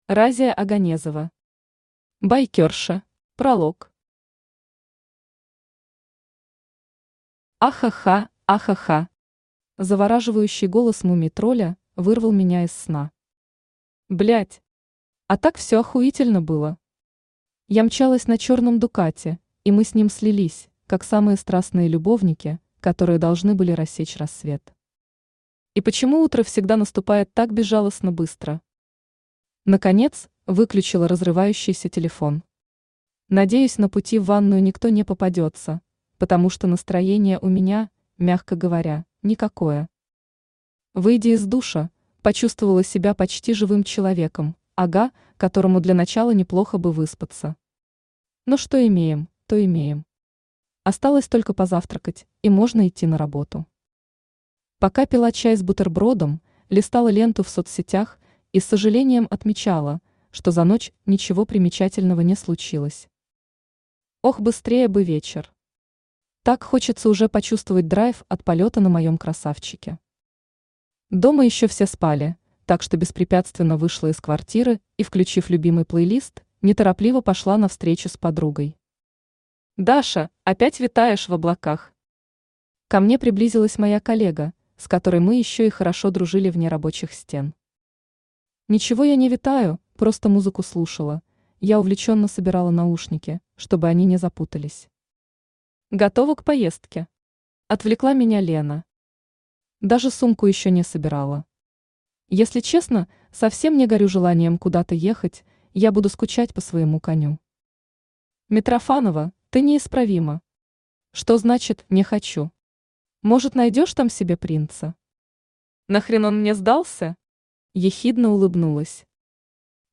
Aудиокнига Байкерша Автор Разия Оганезова Читает аудиокнигу Авточтец ЛитРес.